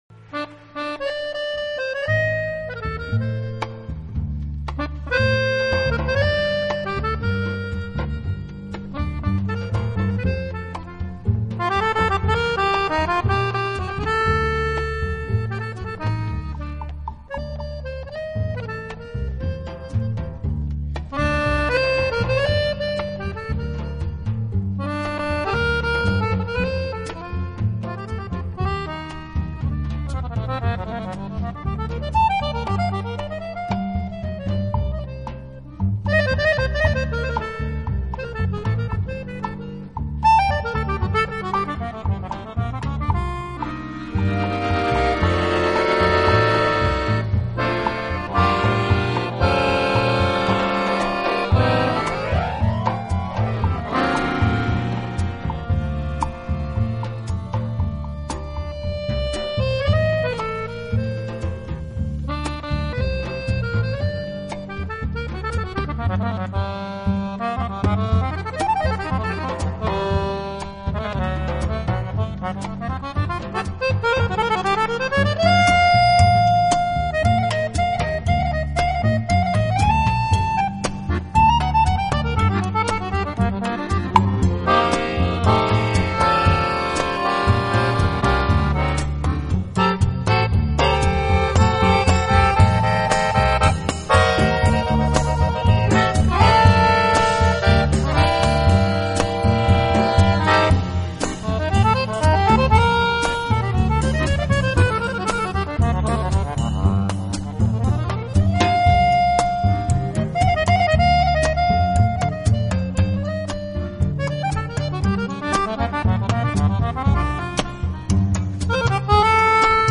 轻音爵士
风格: Nu-Soul, Contemporary Jazz, World Music, Latin